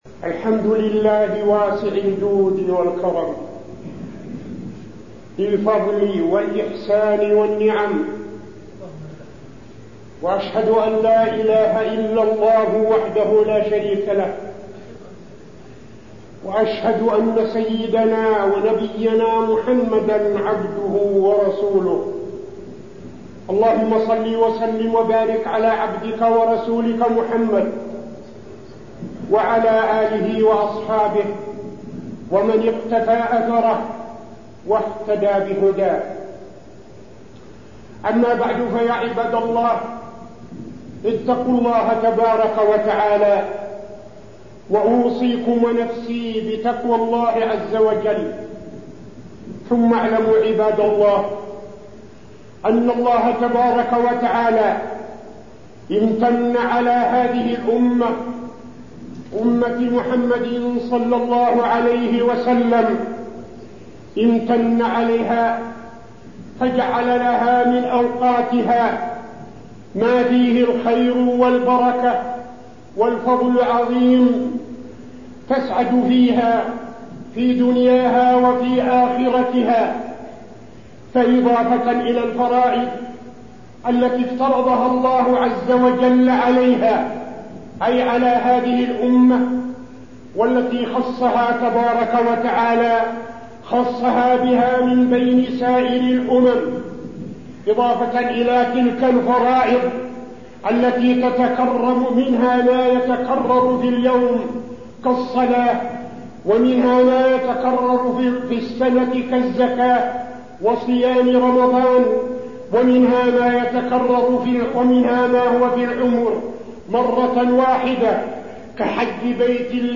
تاريخ النشر ٦ محرم ١٤٠٦ هـ المكان: المسجد النبوي الشيخ: فضيلة الشيخ عبدالعزيز بن صالح فضيلة الشيخ عبدالعزيز بن صالح فضل صيام يوم عاشوراء